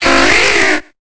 Cri de Kokiyas dans Pokémon Épée et Bouclier.